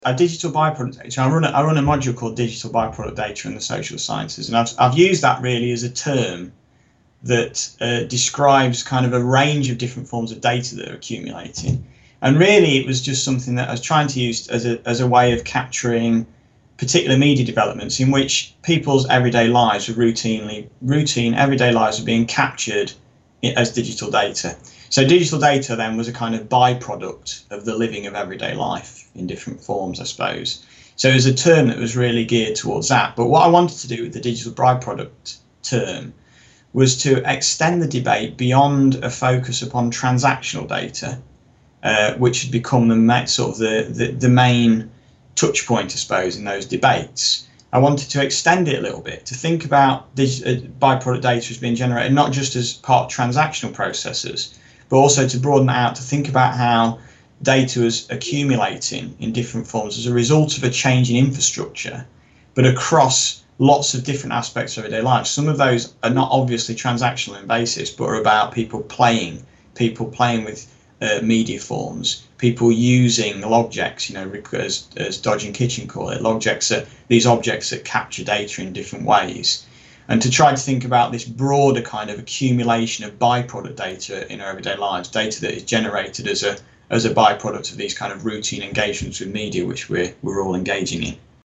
How will sociology cope with digital data? An interview